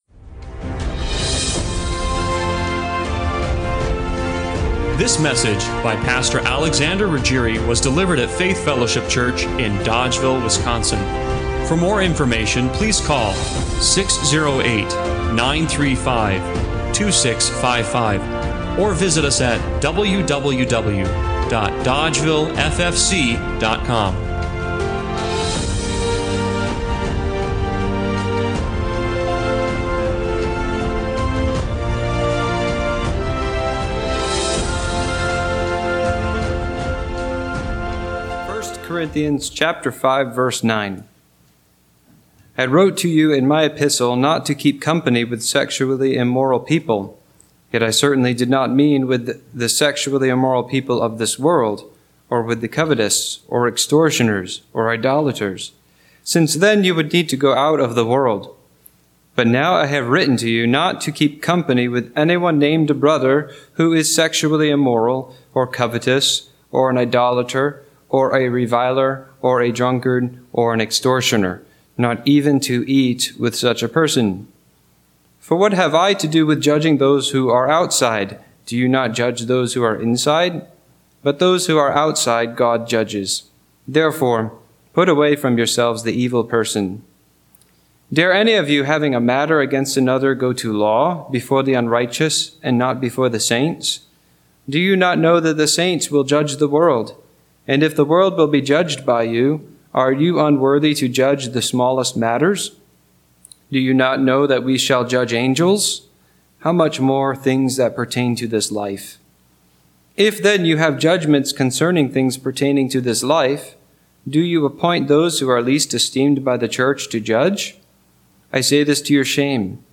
1 Corinthians 5:9-6:8 Service Type: Sunday Morning Worship God is passionately concerned about the purity of His people.